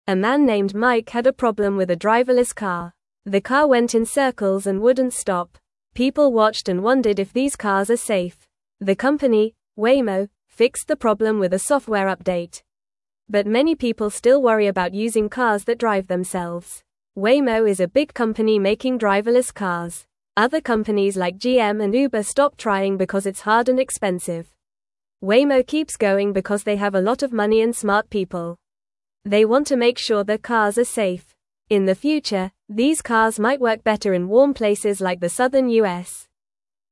Fast
English-Newsroom-Beginner-FAST-Reading-Driverless-Cars-Safe-or-Not-for-Everyone.mp3